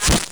strike4.wav